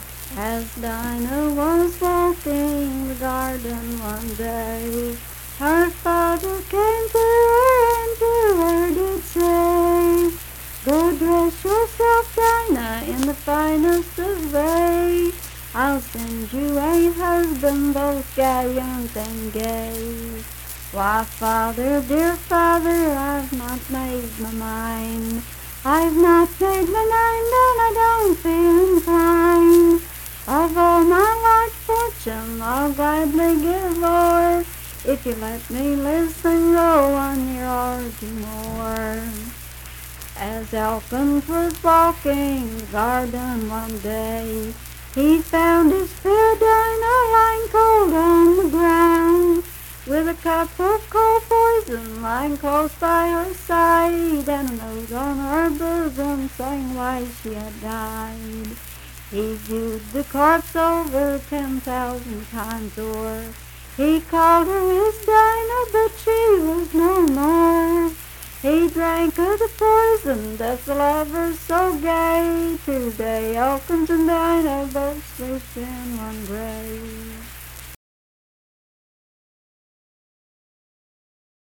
Unaccompanied vocal music
Voice (sung)
Braxton County (W. Va.), Sutton (W. Va.)